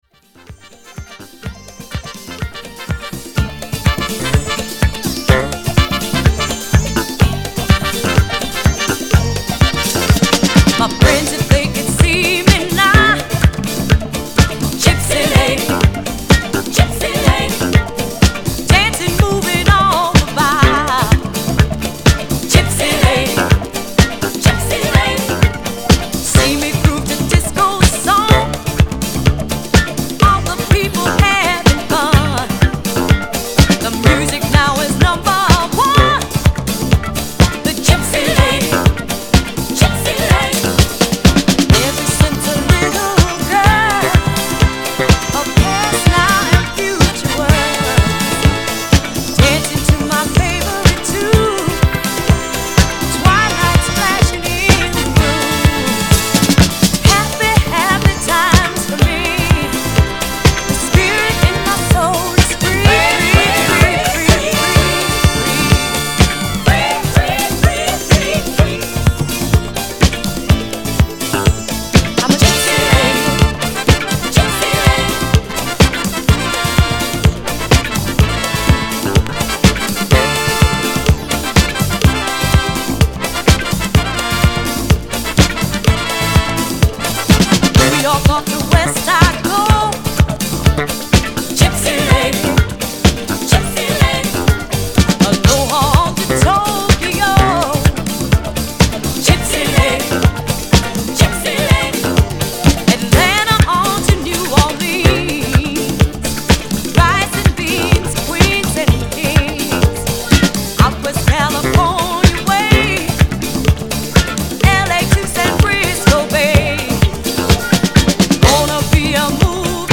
REGGAEというよりもROCK DISCOノリノリ